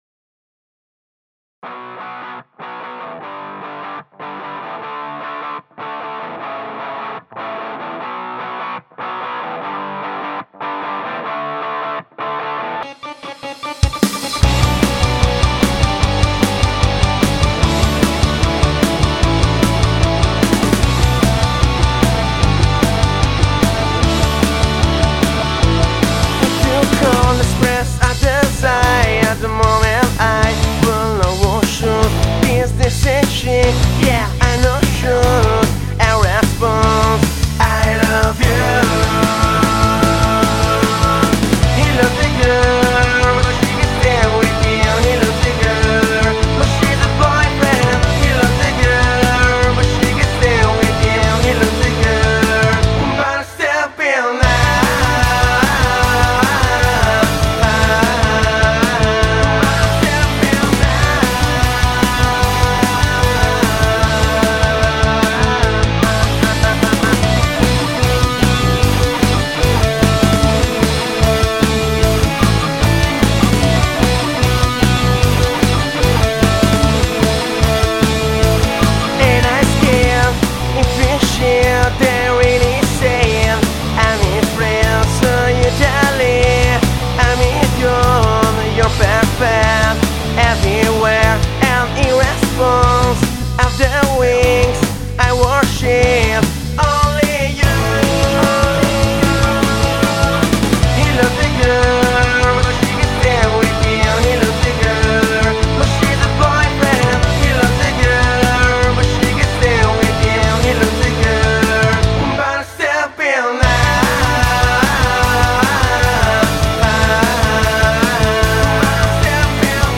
Genere: Pop / Punk